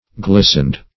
Glisten \Glis"ten\ (gl[i^]s"'n), v. i. [imp. & p. p.